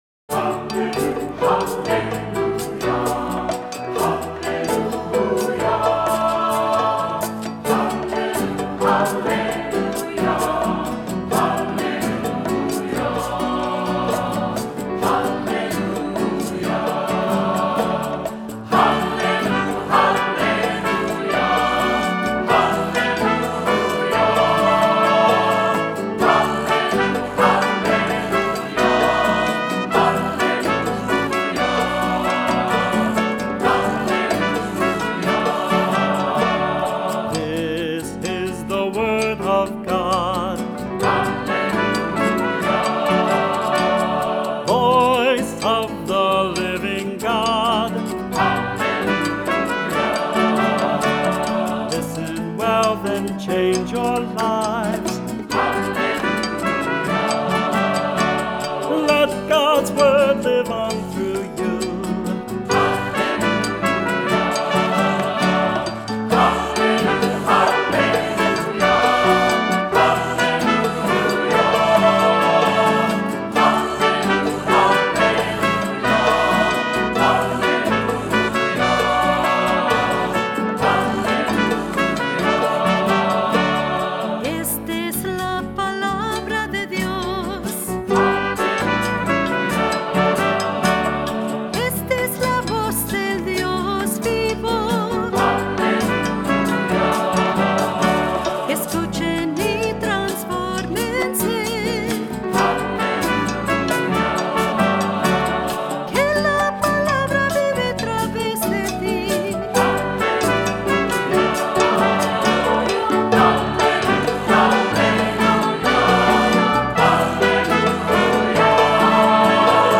Voicing: Cantor, assembly,SATB